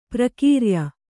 ♪ prakīrya